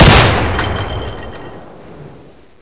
30cal_shoot.wav